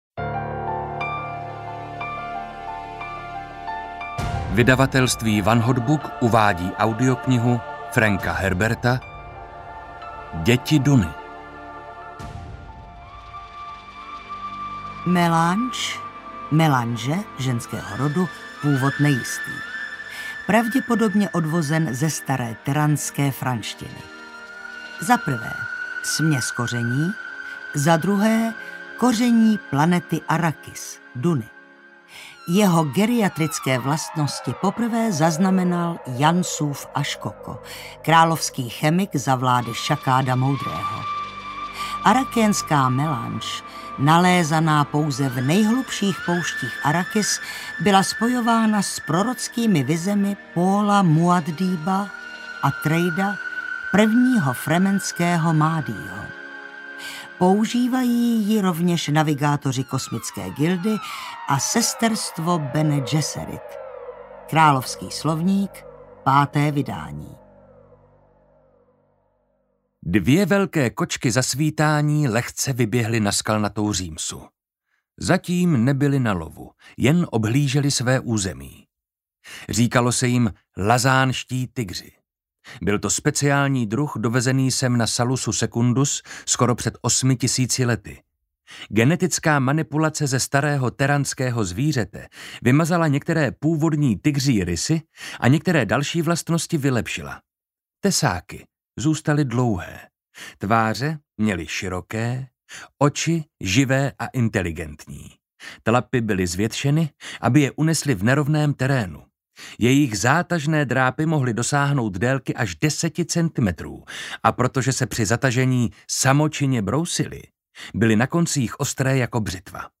Děti Duny audiokniha
Ukázka z knihy
• InterpretMarek Holý, Zuzana Slavíková